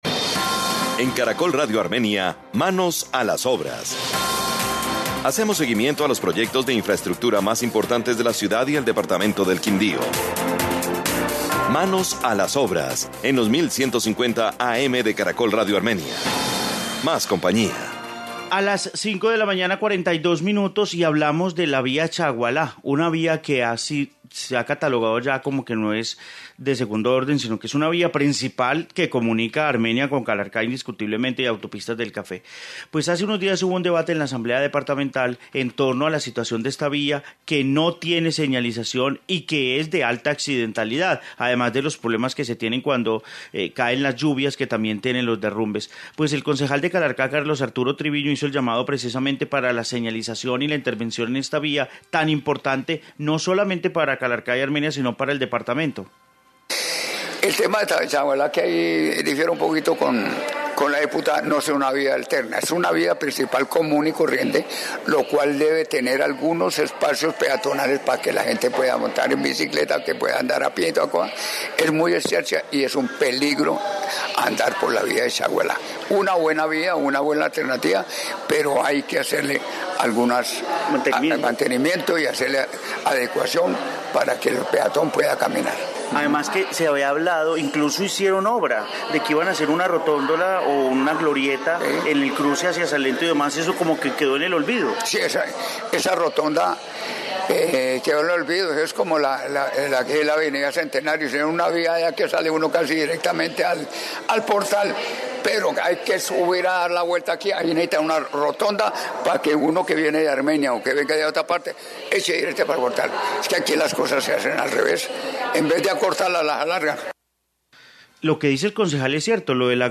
Informe vía chaguala, Quindío